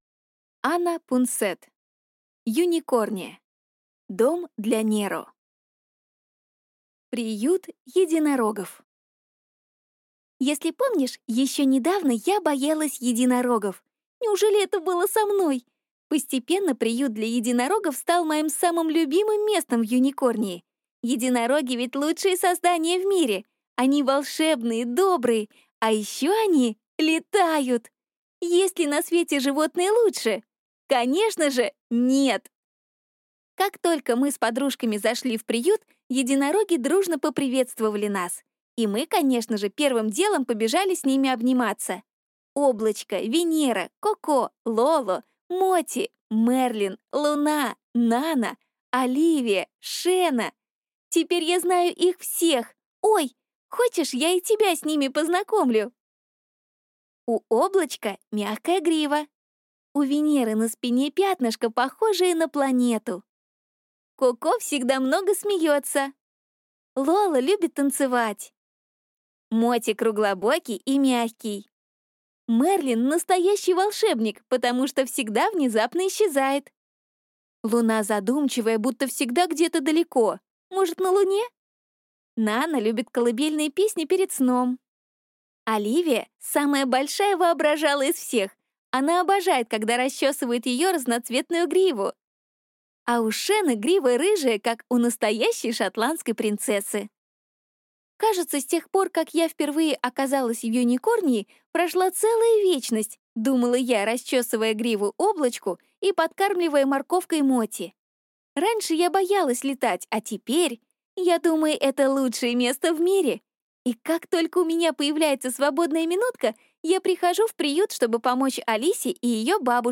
Аудиокнига Юникорния. Дом для Неро | Библиотека аудиокниг